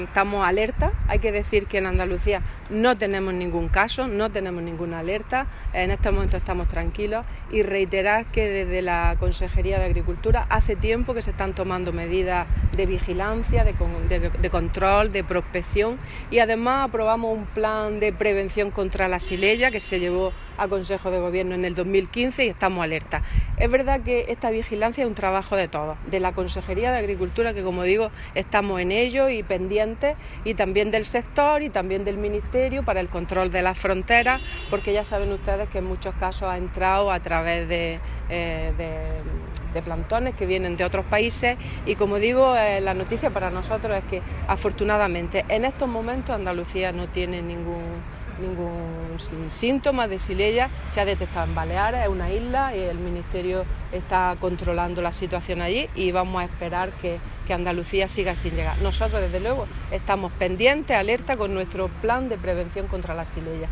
La consejera Carmen Ortiz, en su intervención en el foro de Diario Jaén.
Declaraciones consejera foro (2)